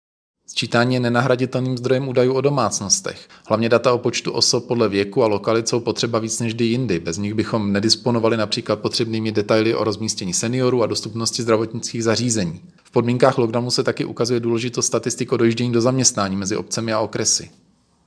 Vyjádření předsedy ČSÚ Marka Rojíčka